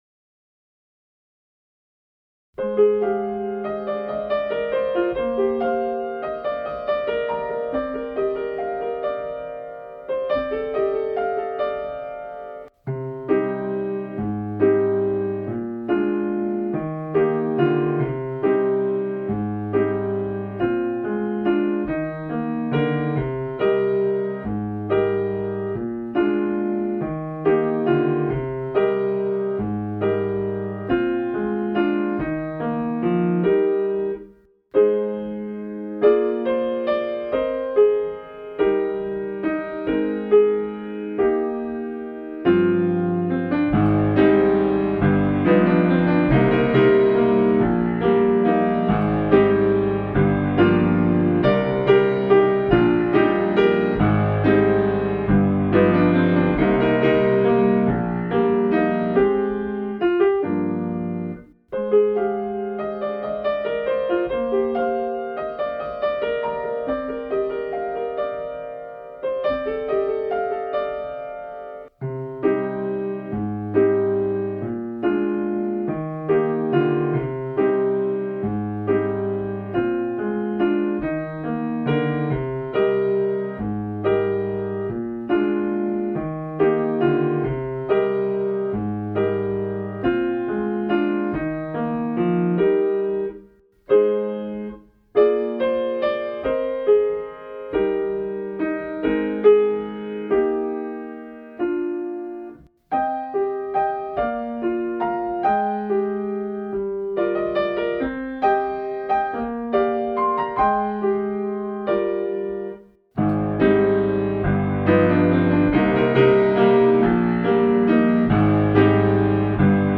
【Instrumental / リマスター版2025】 mp3 DL ♪